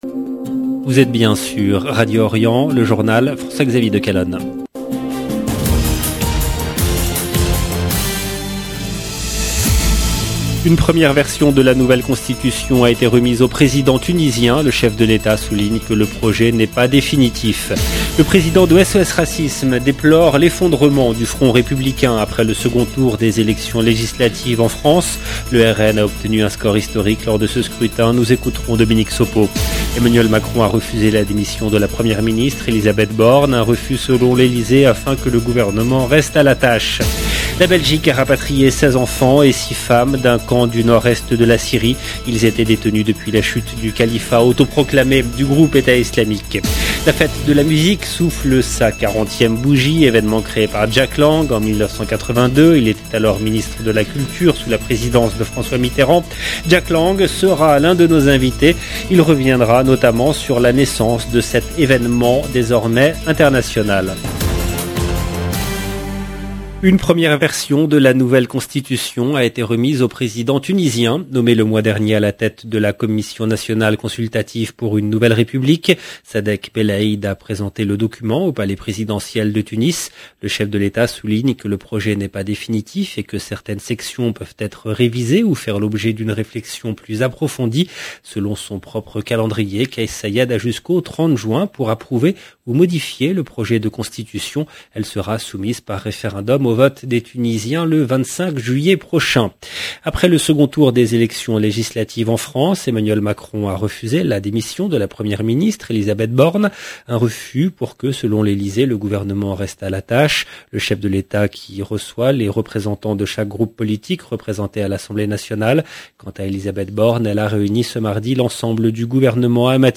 LE JOURNAL DU SOIR EN LANGUE FRANCAISE DU 21/06/22 LB JOURNAL EN LANGUE FRANÇAISE
Jack Lang sera l’un de nos invités.